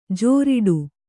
♪ jōriḍu